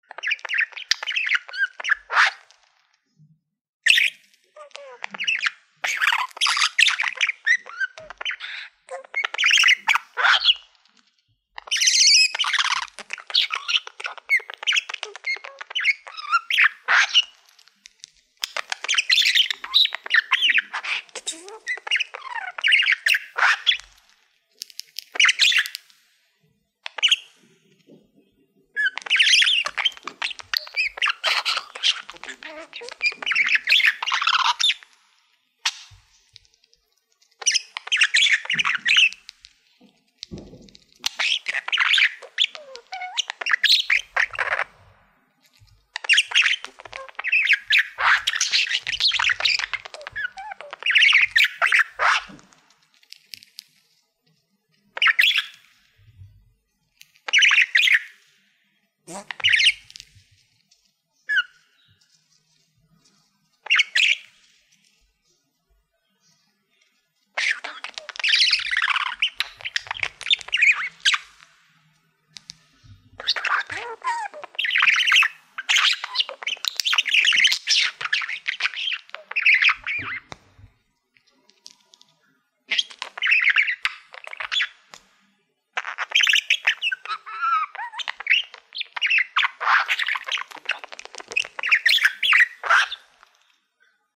دانلود صدای مرغ عشق مست و خوشحال و در حال بازی از ساعد نیوز با لینک مستقیم و کیفیت بالا
جلوه های صوتی